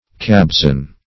Cabezon \Cab`e*zon"\ (k[a^]b`[asl]*z[o^]n" or